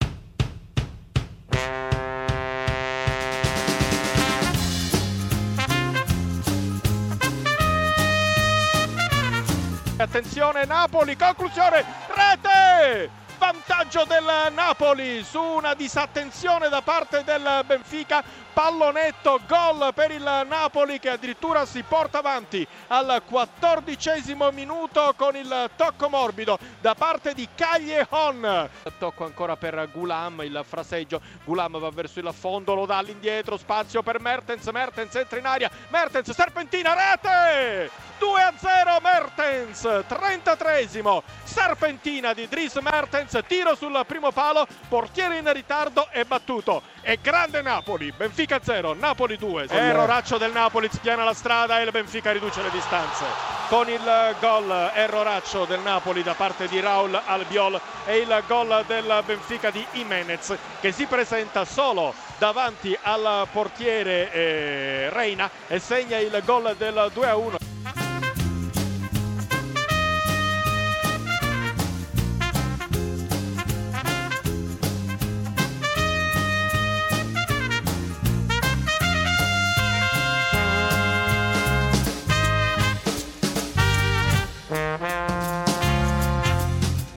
Benfica-Napoli 1-2-La radiocronaca dei gol